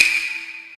normal-hitwhistle.mp3